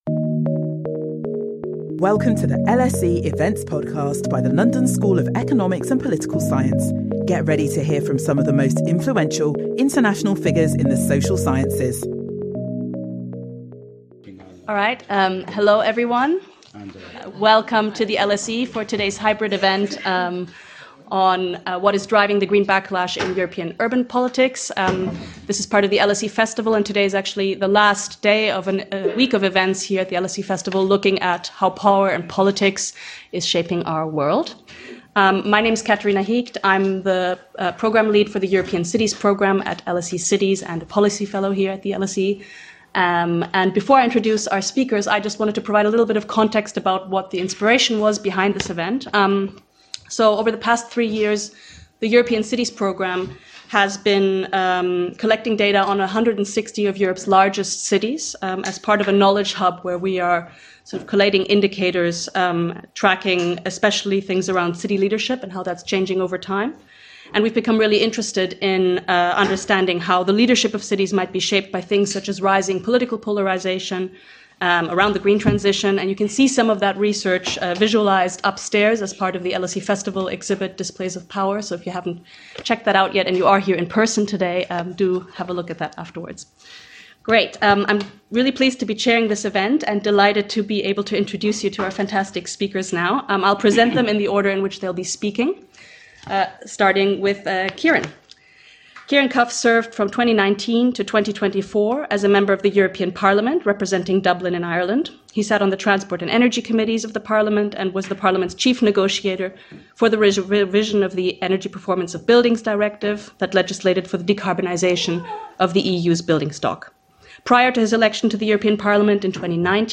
Our panel of panel of European city leaders discuss what has been driving a growing backlash against the green transition in Europe, and how this is shaping urban politics and policy making.